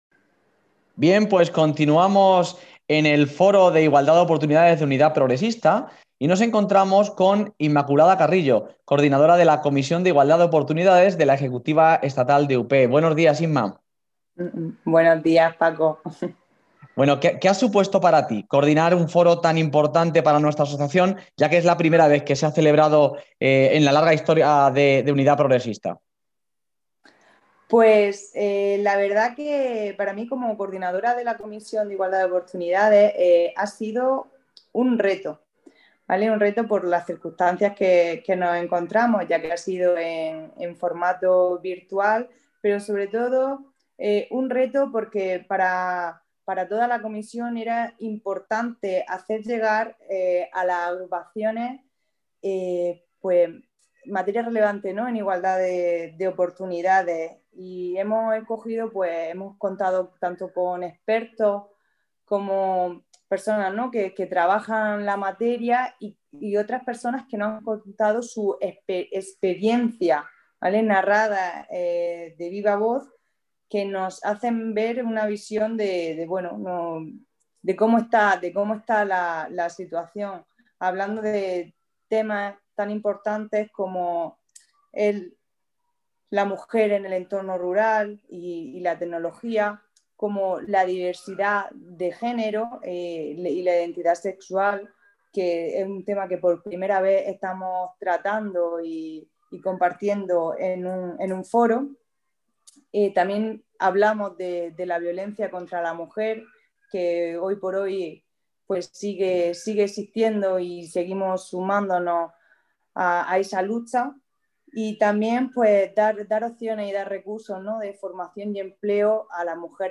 A continuación os compartimos algunos testimonios sonoros recogidos en el Foro: